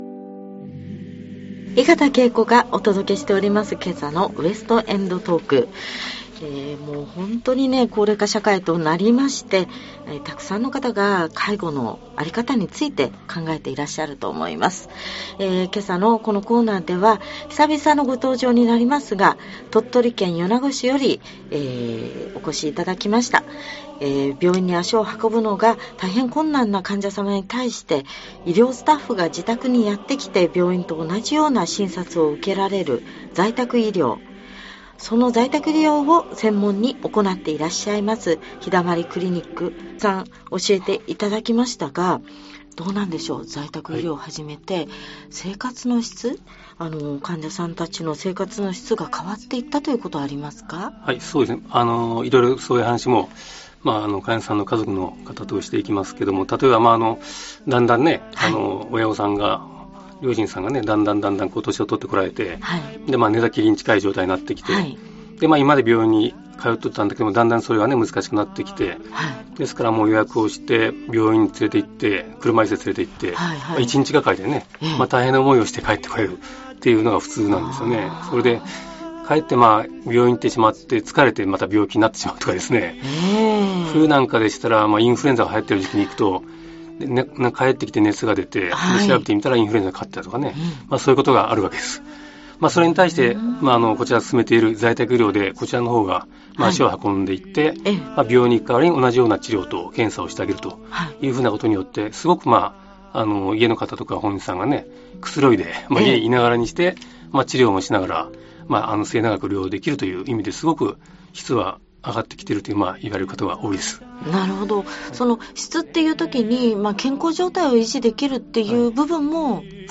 ラジオ『West end talk(FM-FUJI 78.6MHz)』『生活の質の向上』『在宅医療の現場』(H27.6.28)